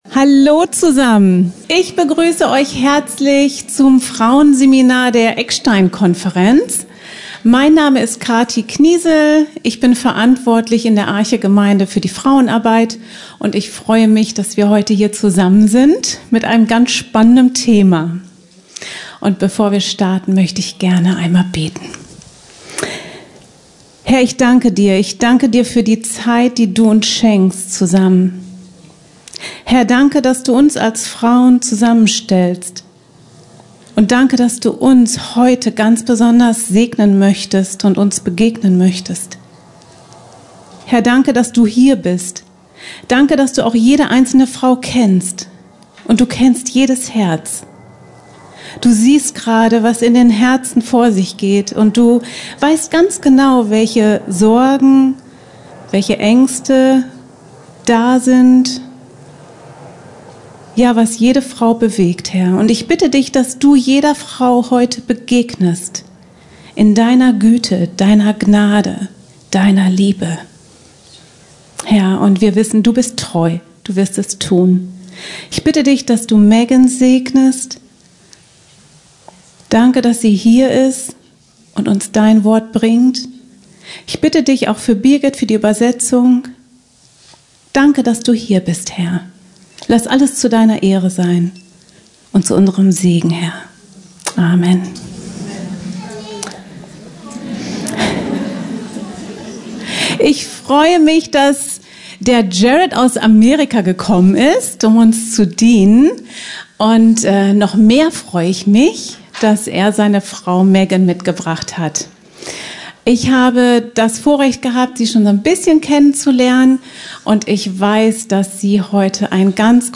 Seminar nur für Frauen: Frieden für das ängstliche Herz: was ich aus dem Krebsleiden meiner Tochter lernte (Eckstein 2025 – Seminar 3) | 1.